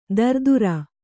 ♪ dardura